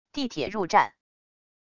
地铁入站wav音频